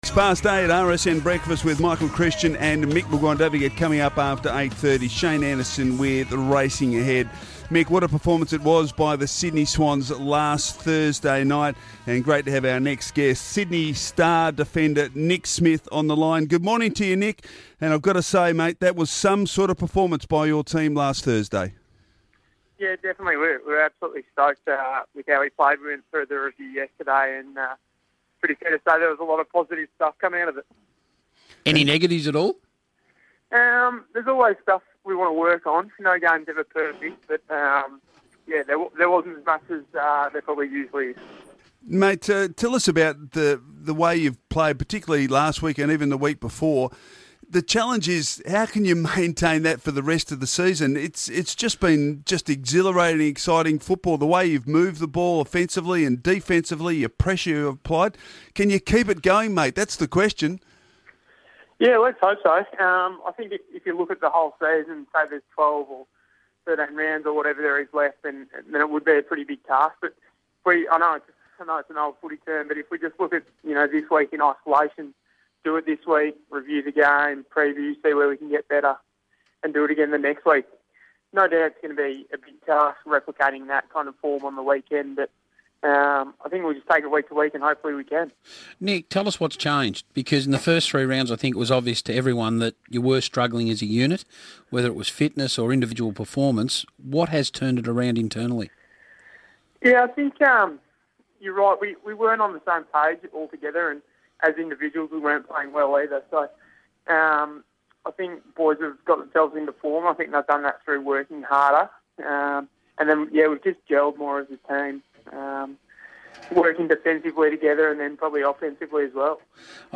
Sydney Swans defender Nick Smith appeared on Radio Sports National on Tuesday June 3, 2014